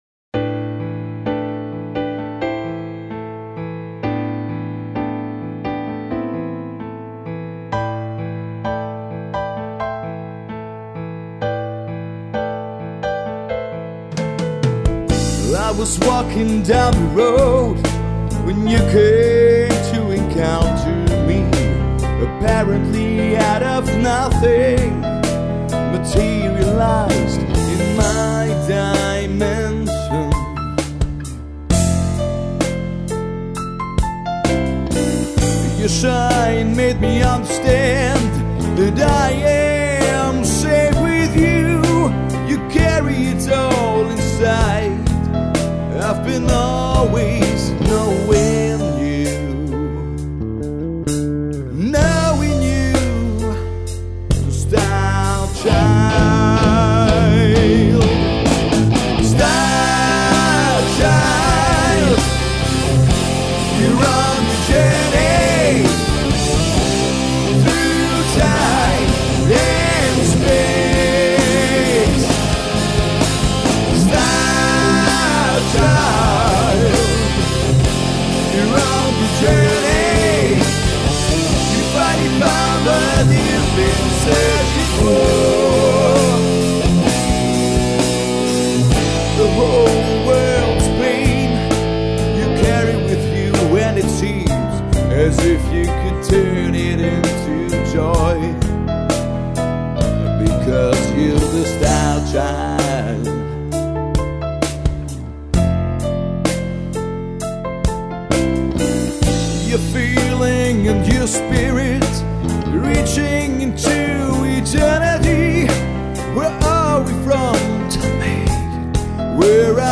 vocals / guitars
keys
bass / backing vocals
drums / backing vocals